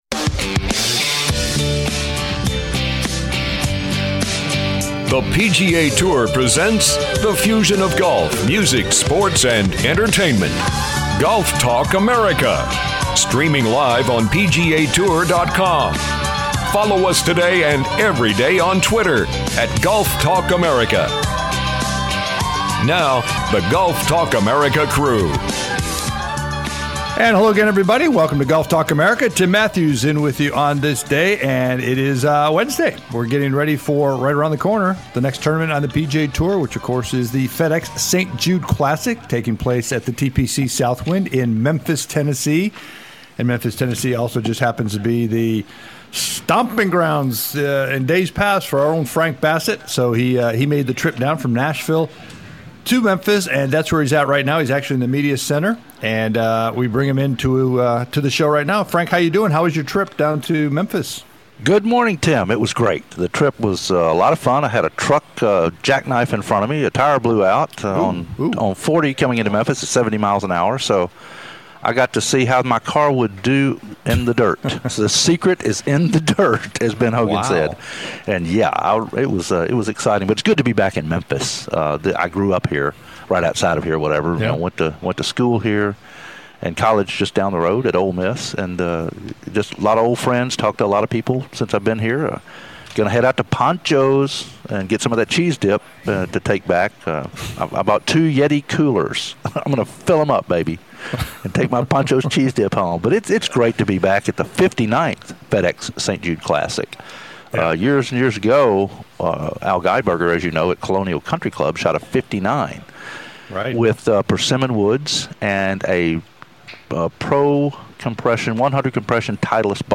GTA "LIVE" from The FedEx St. Jude Classic at TPC Southwind